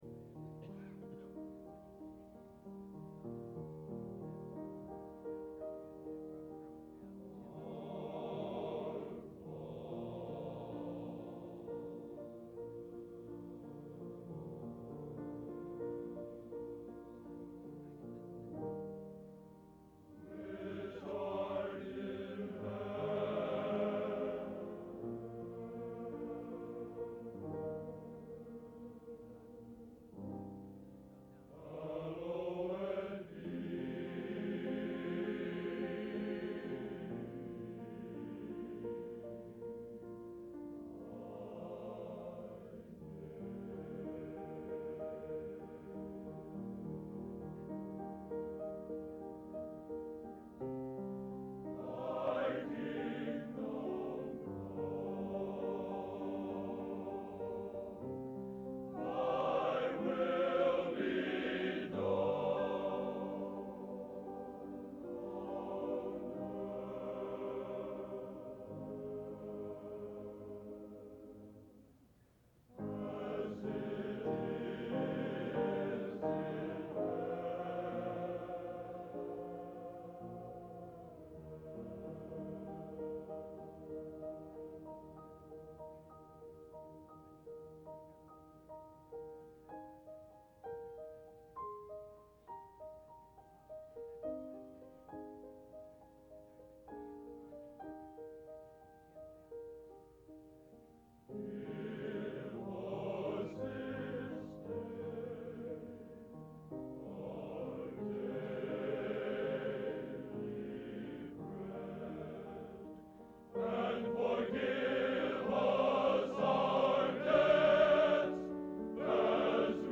Genre: Sacred | Type: